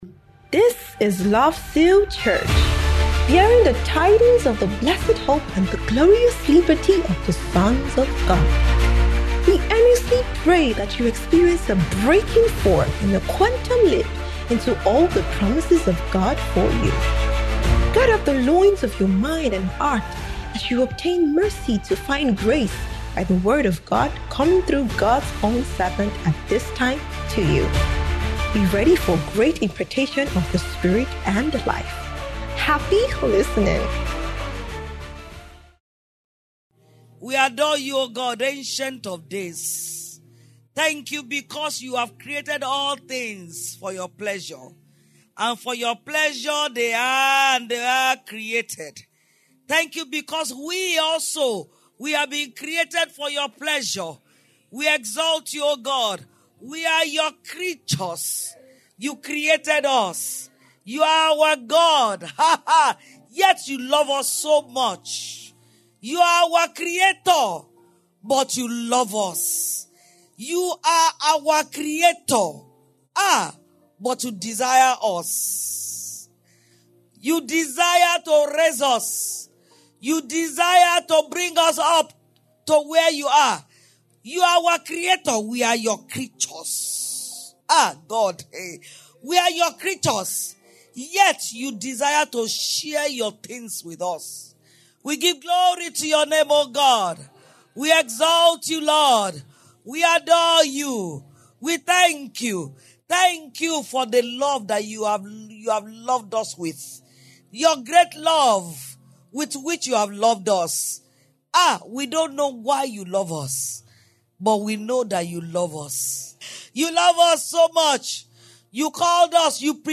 Annual Year-End Thanksgiving Service